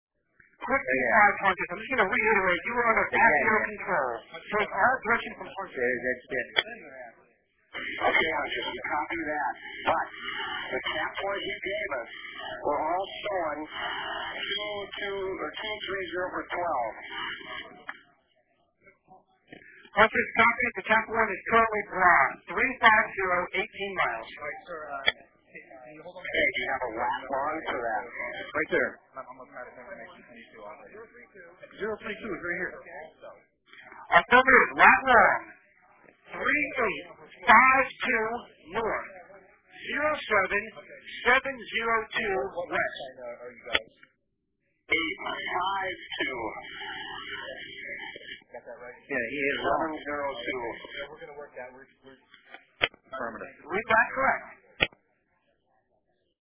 In a subsequent exchange with the controller at 0952 EDT, the accurate coordinates, 3852N 07702W, were provided.
Here is the later audio clip from the same NEADS tape/channel.